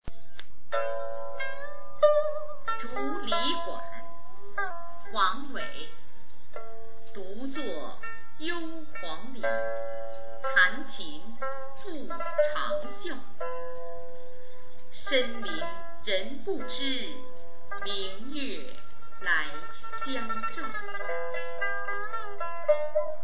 （链接：朗诵）